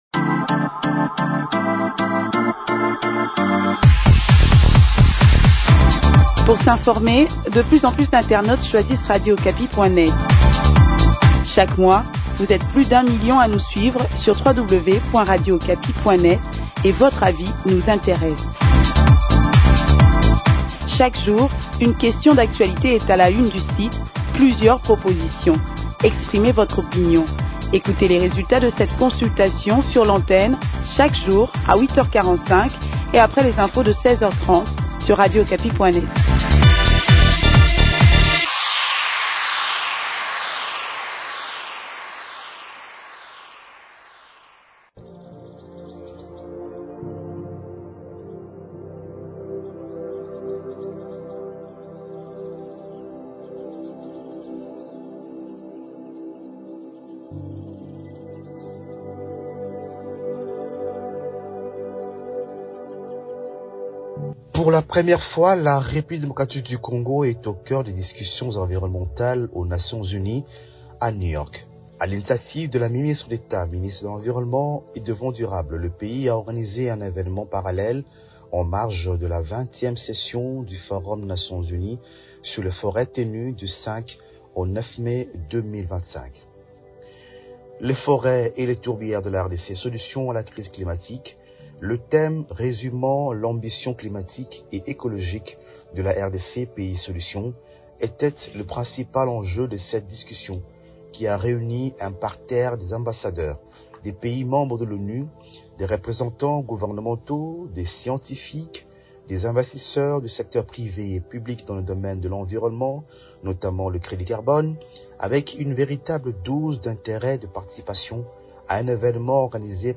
Des plus amples détails dans ce grand-reportage.